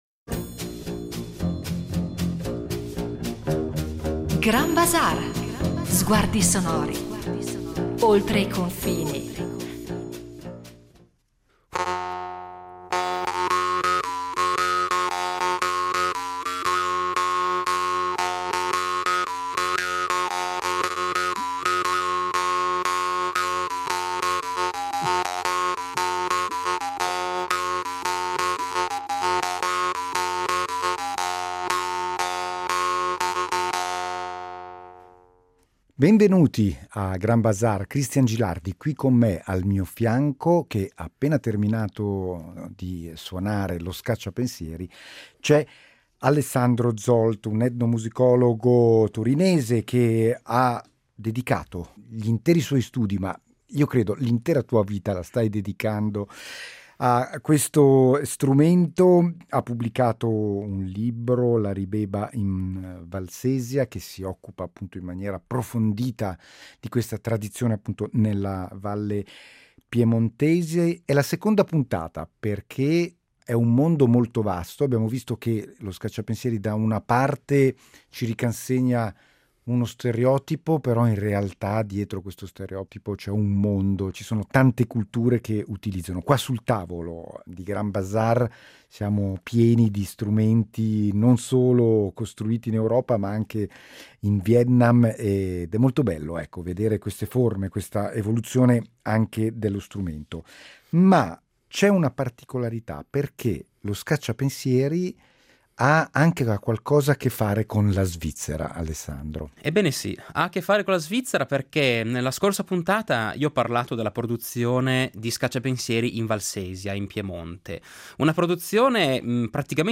Grand Bazaar in due puntate cercherà di tracciare la storia di questo antico strumento assieme all’etnomusicologo